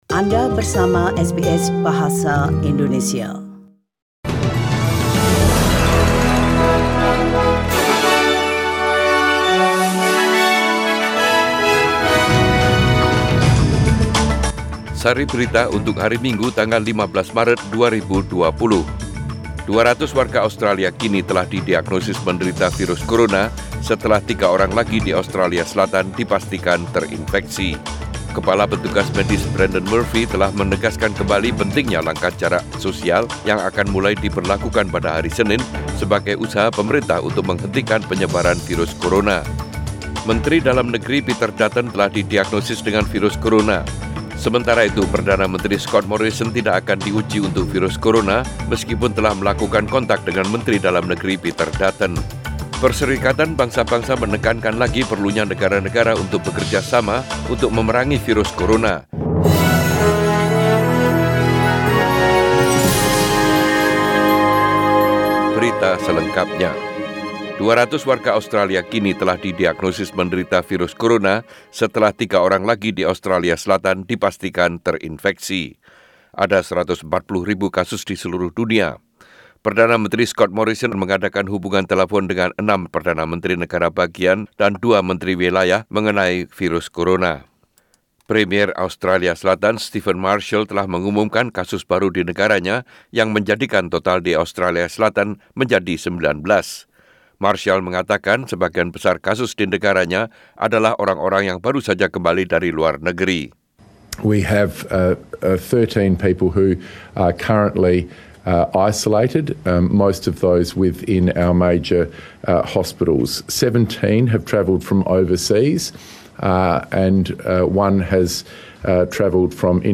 SBS Radio News in Bahasa indonesia - 15 March 2020